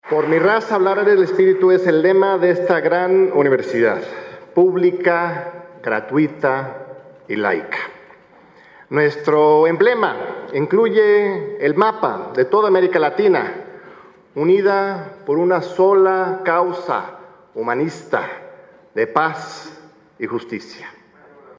Al dictar la conferencia “El nuevo modelo de integración Latinoamericano”, en el Anfiteatro Simón Bolívar, del Antiguo Colegio de San Ildefonso, se asumió como gran defensor de la educación pública y de la universidad gratuita.